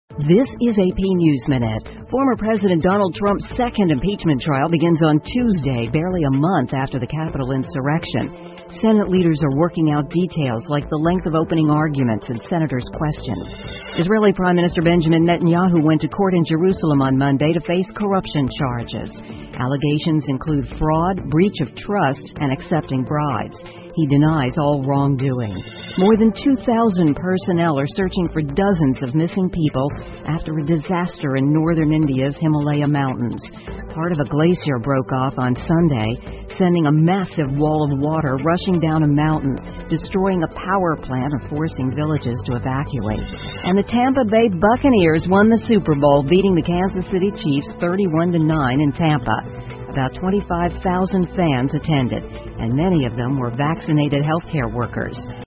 美联社新闻一分钟 AP 印度北部冰川断裂致数人死亡 听力文件下载—在线英语听力室